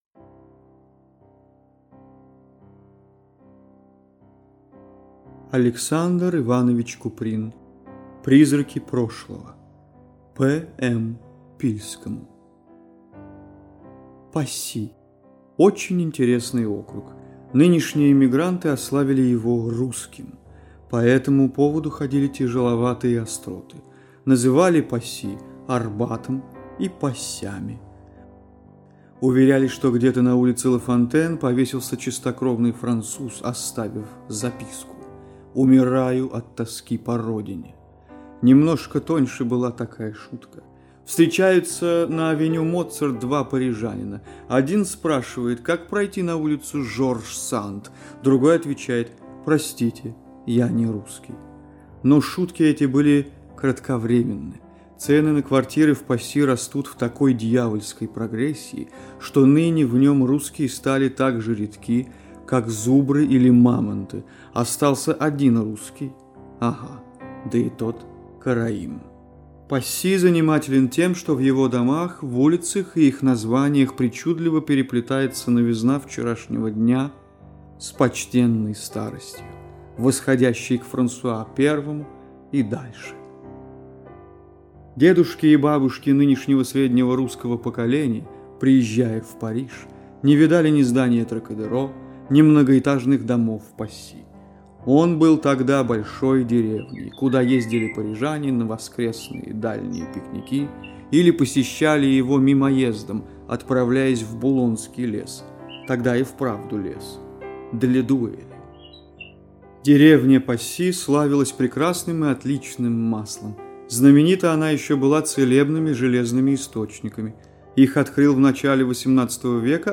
Аудиокнига Призраки прошлого | Библиотека аудиокниг